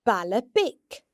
The non-slender L sounds much like the English ‘L’ sound, and can be heard in baile (a town):